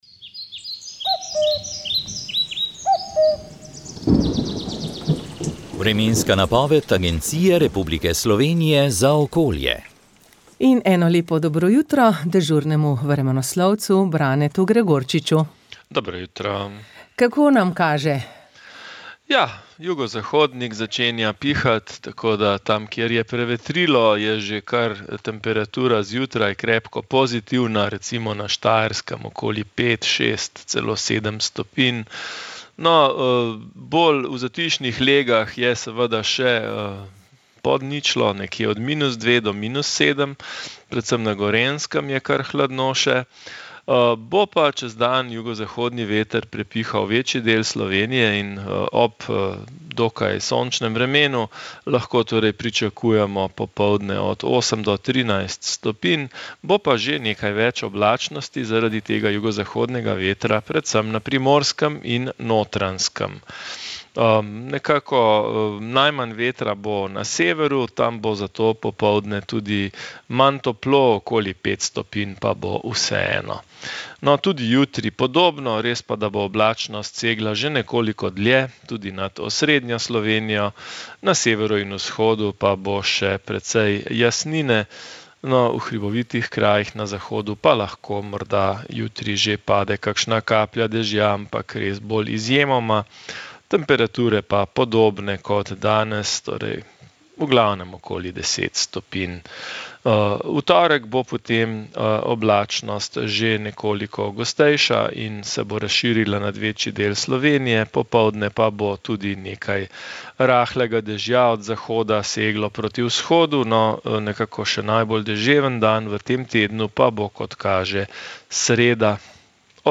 Vremenska napoved 25. november 2024